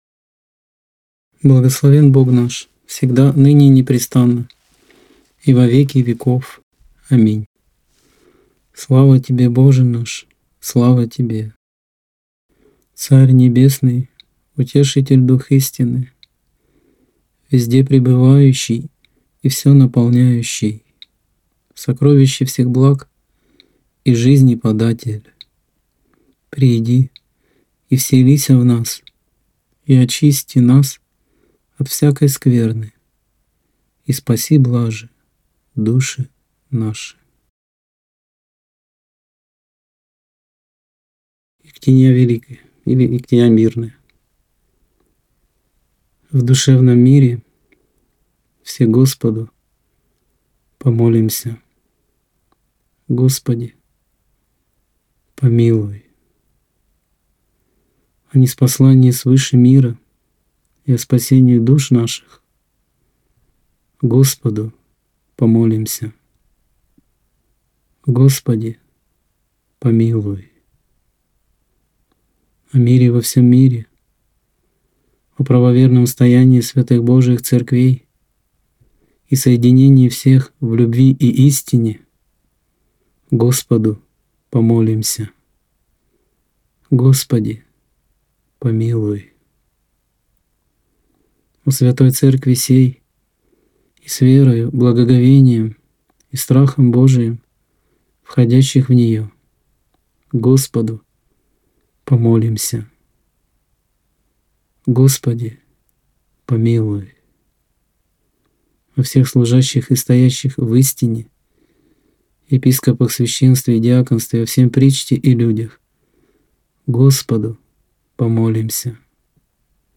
Молитва Святому Духу. (00:41) Ектения Великая.